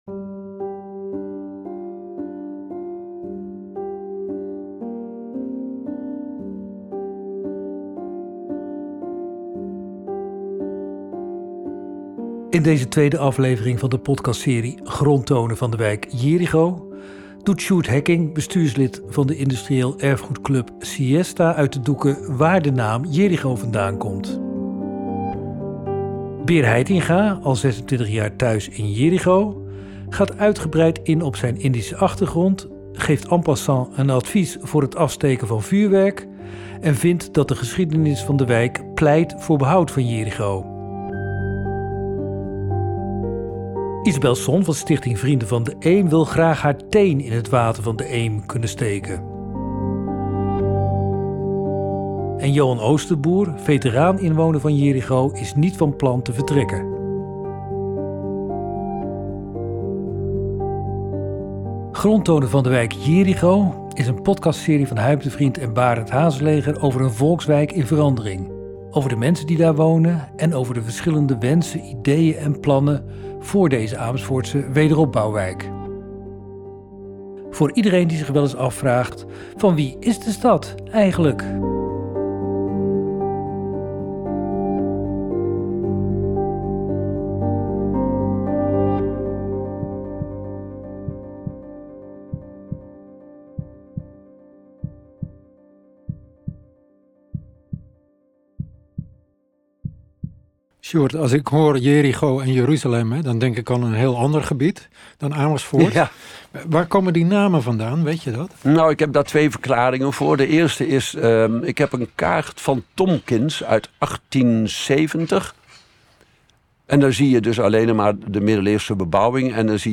podcast op locatie over landschap en natuur, cultuur, politiek, wetenschap, werk en wonen